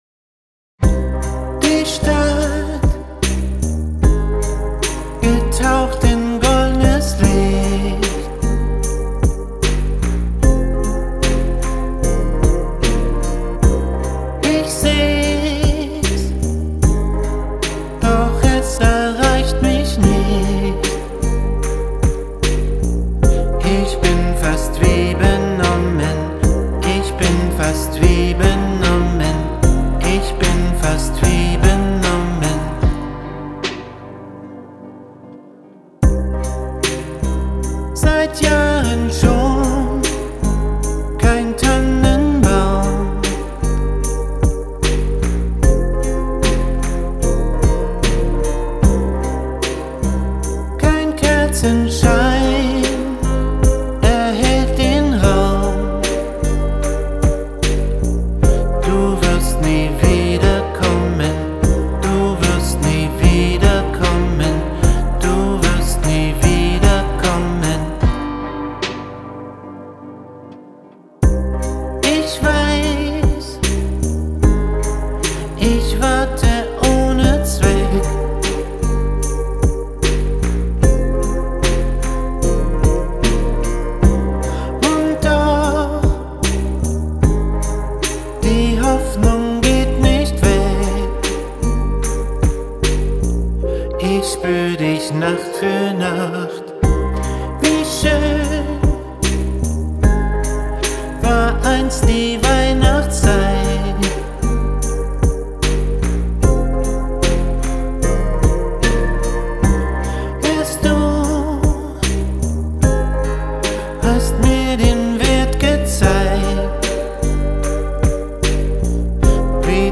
Okay, ich stelle fest, die Tonlage ist doch zu hoch für mich. Ich habe mir dabei ganz schön einen abgewürgt.